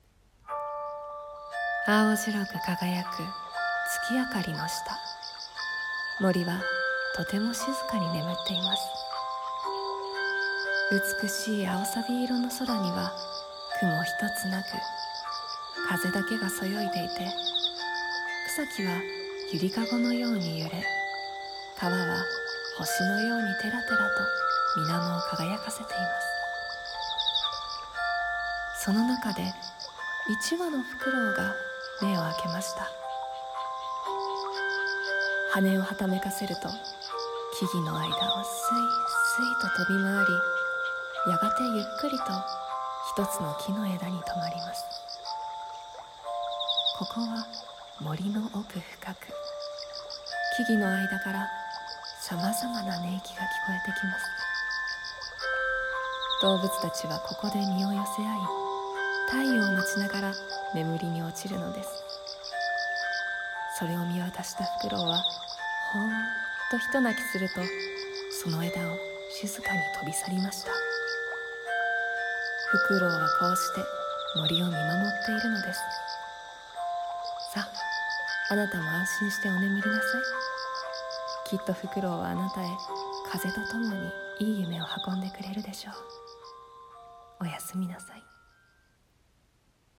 声劇 ふくろうの森